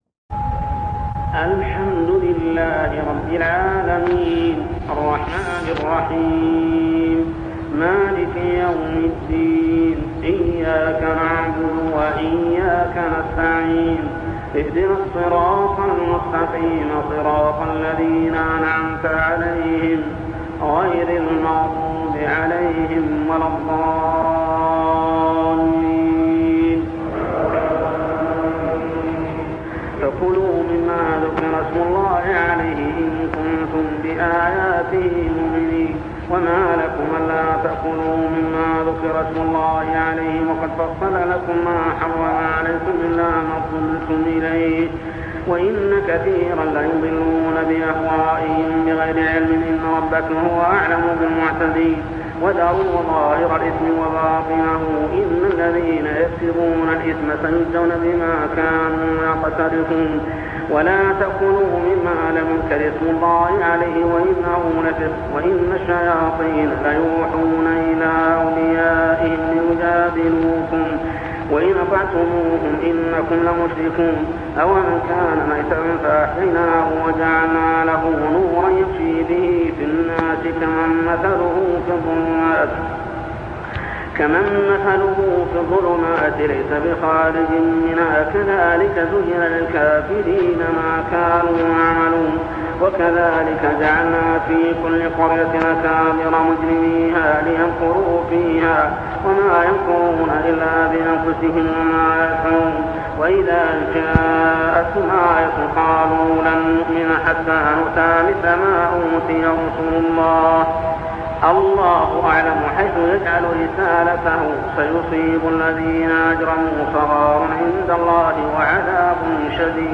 صلاة التراويح عام 1401هـ سورتي الأنعام 118-165 و الأعراف 1-9 | Tarawih prayer Surah Al-An'am and Al-A'raf > تراويح الحرم المكي عام 1401 🕋 > التراويح - تلاوات الحرمين